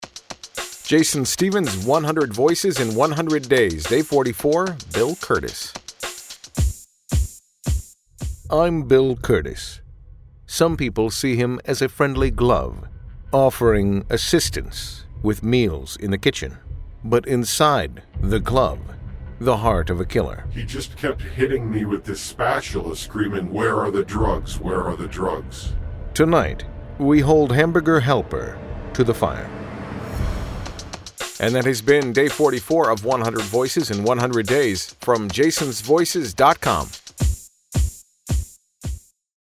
Day 44 – Bill Kurtis Impression
Tags: Bill Kurtis voice, celebrity voice match